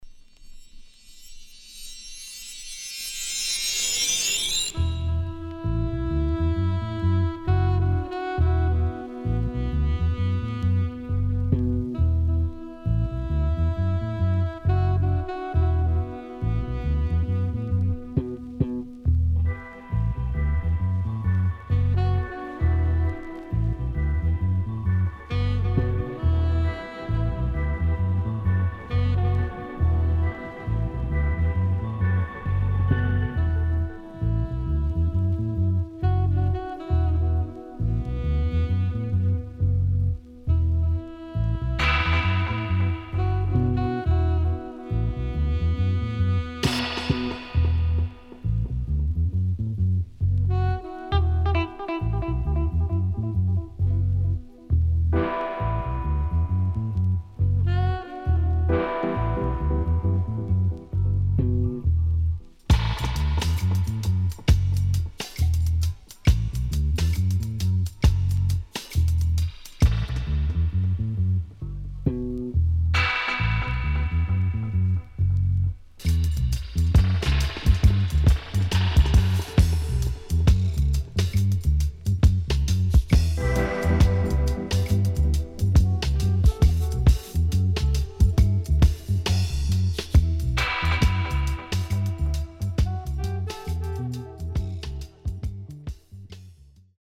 HOME > LOVERS  >  INST 70's
【12inch】
SIDE A:少しチリノイズ入りますが良好です。